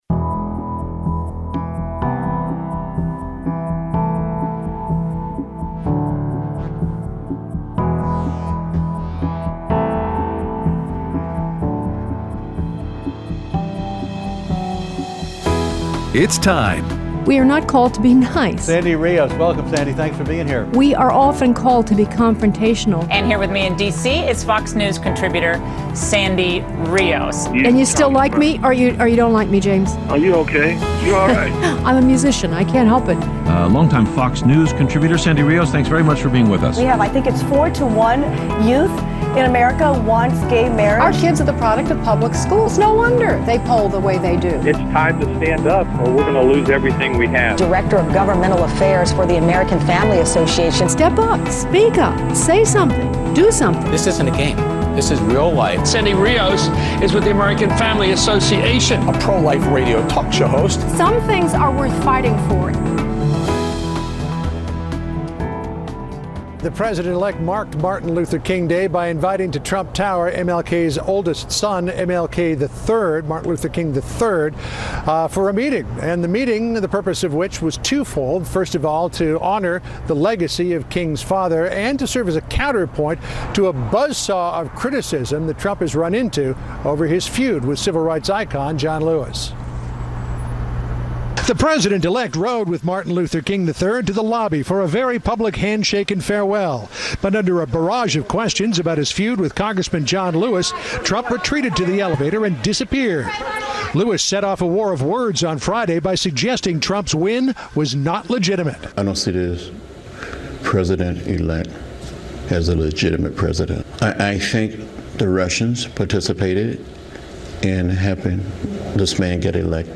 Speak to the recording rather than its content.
Aired Tuesday 1/17/17 on AFR 7:05AM - 8:00AM CST